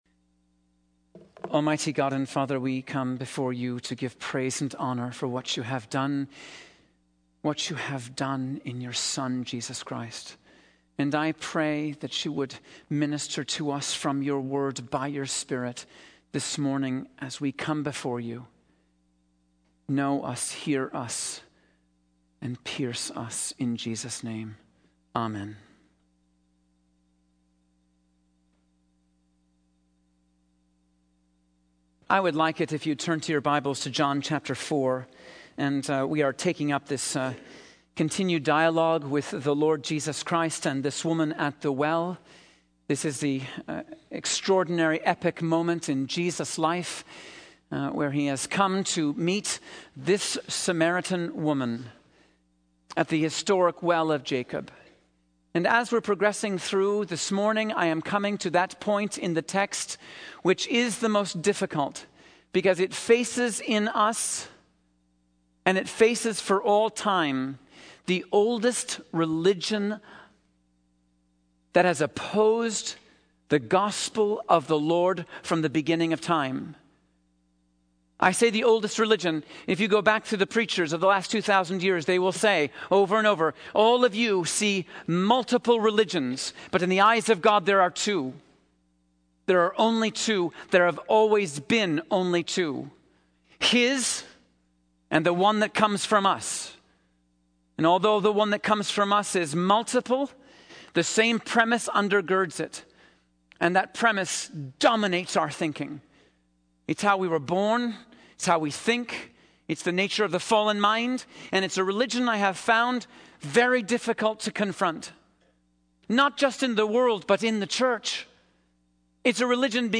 In this sermon, the preacher focuses on the concept of sin and the need for a savior. He refers to a well-known verse in Romans chapter three, verse 23, which states that all have sinned and fall short of the glory of God.